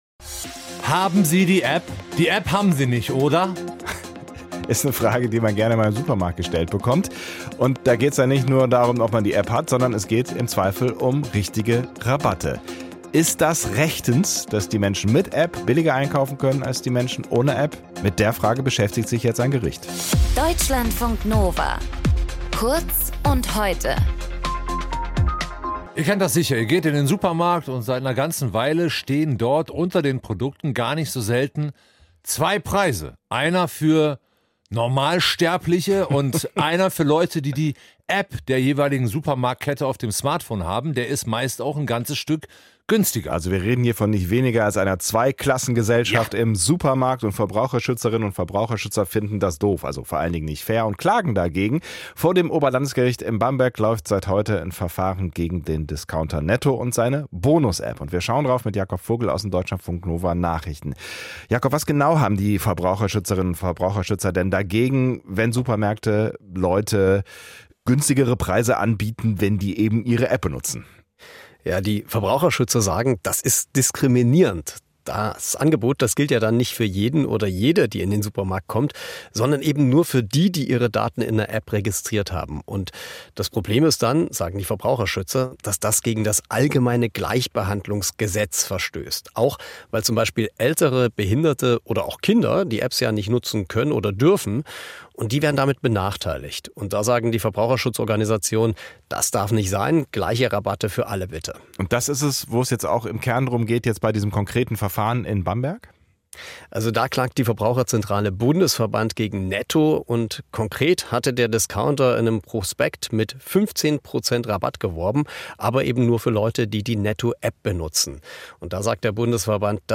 Moderation
Gesprächspartner